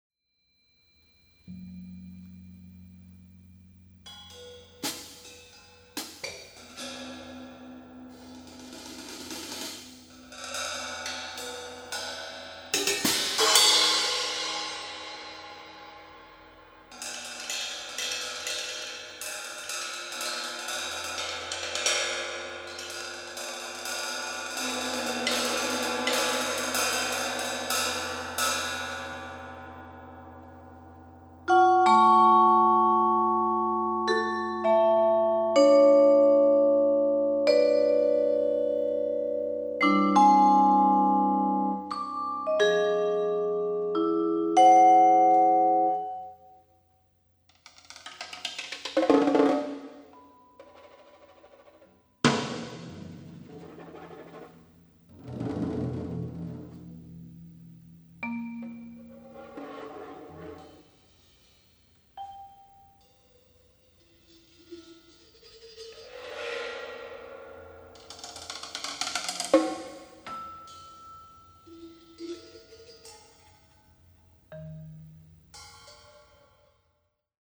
Percussion
Rec: 5. Nov. 2017, Düsseldorf, (UA)